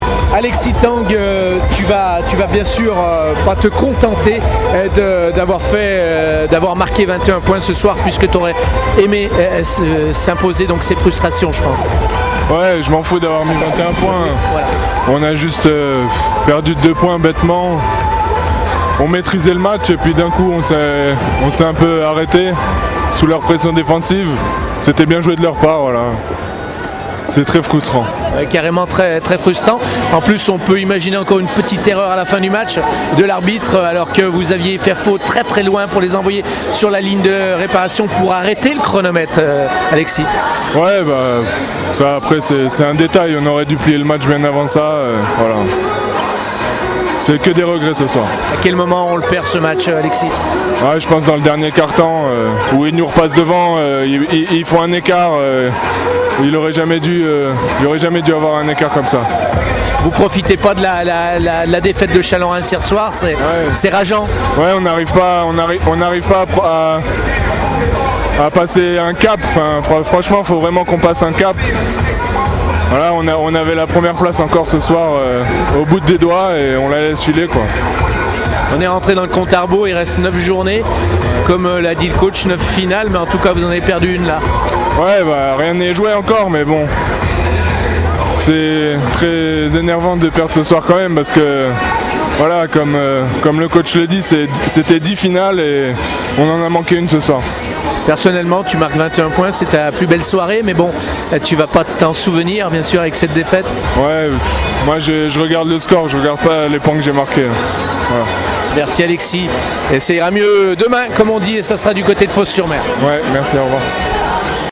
réactions d’après-match